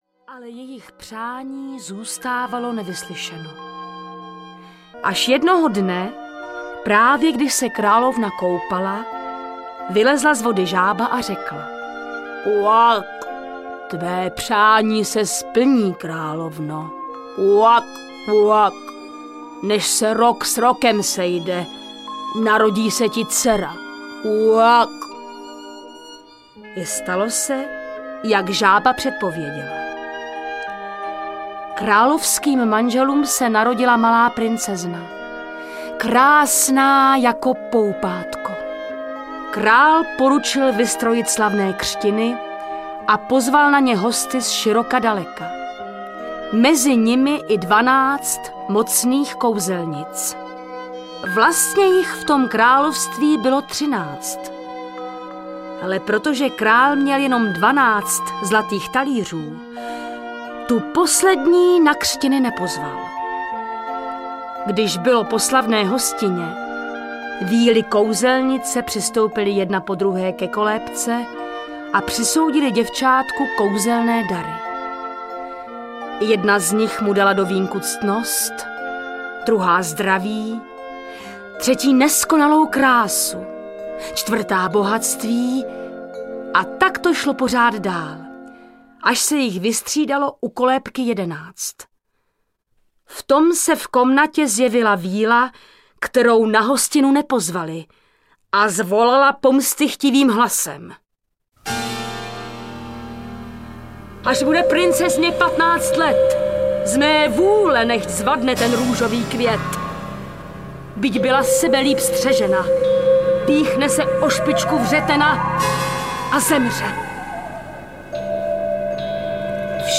Adventní pohádkový kalendář audiokniha
Ukázka z knihy
Viktor Preiss, Luděk Munzar, Jana Hlaváčová, Václav Vydra, Jana Boušková, Otakar Brousek a řada dalších herců interpretuje klasické české pohádky a také pohádky s vánoční a zimní tématikou.